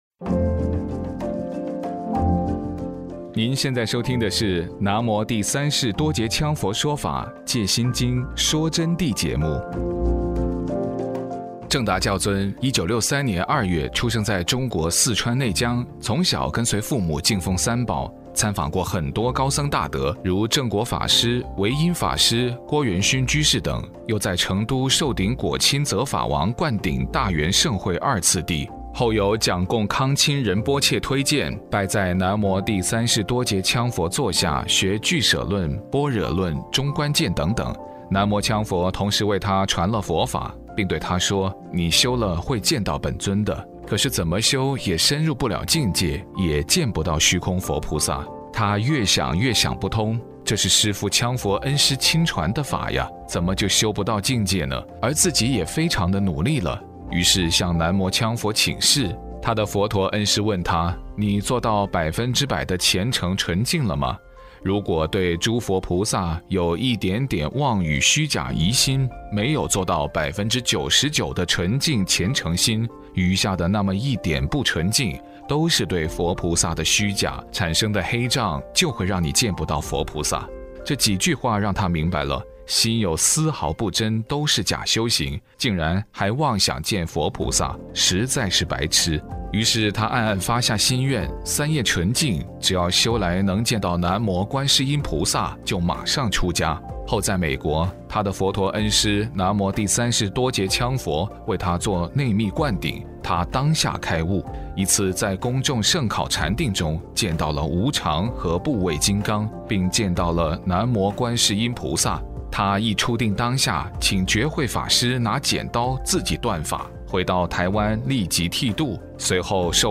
佛弟子访谈（五十七）南无羌佛在五明圣量中的内明所展显的预言圣迹